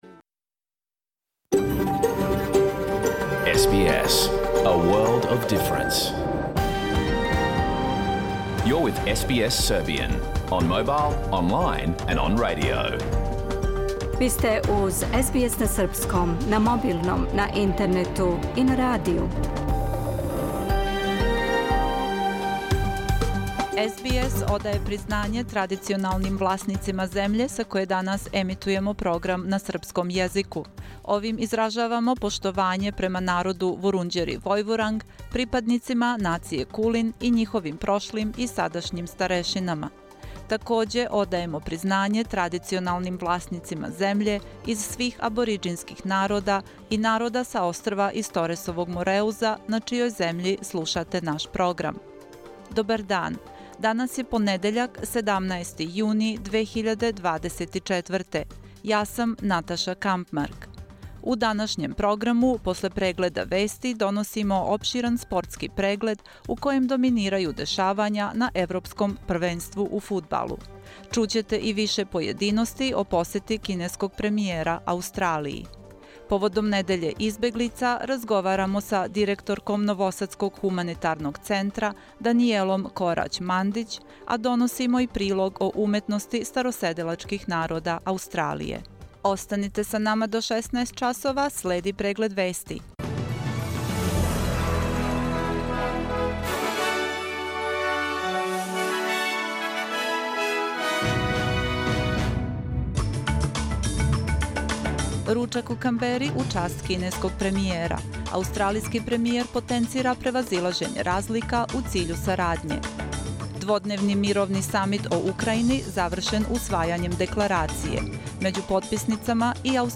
Програм емитован уживо 17. јуна 2024. године
Уколико сте пропустили данашњу емисију, можете је послушати у целини као подкаст, без реклама.